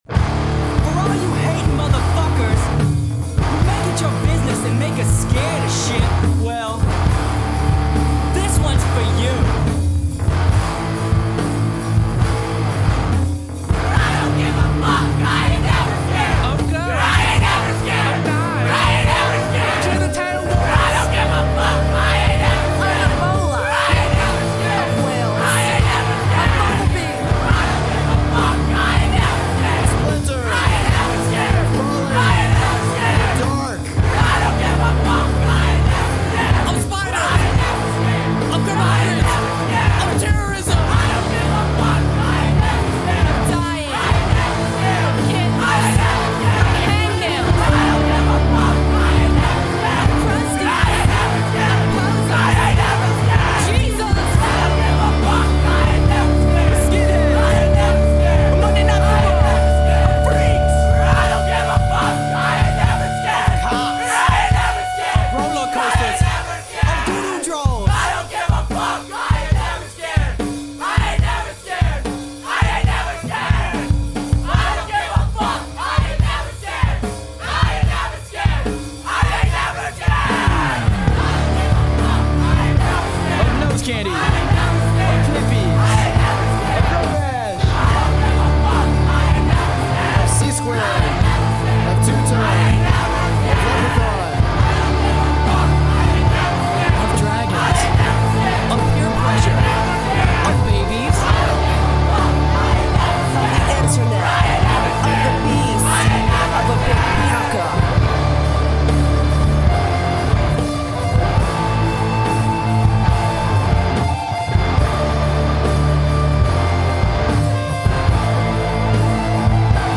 They are a very tongue-in-cheack hardcore band.